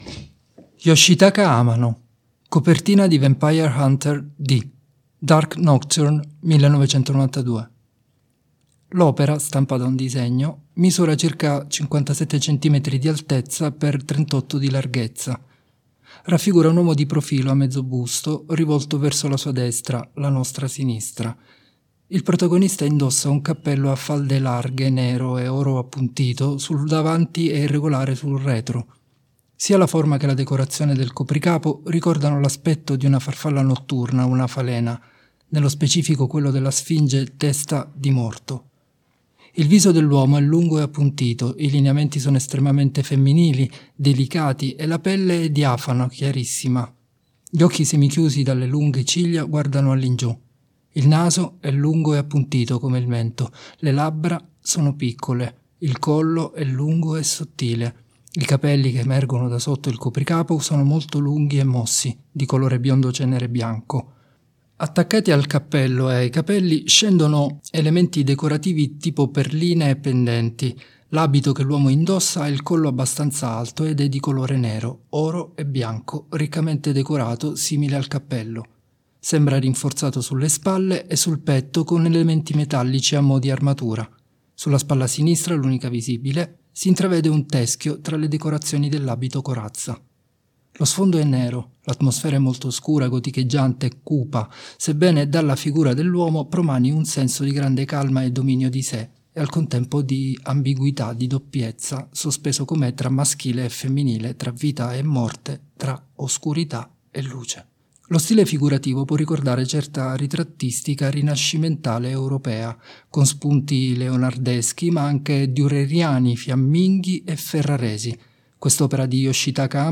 Descrizioni pannelli sensoriali per ciechi e ipo-vedenti: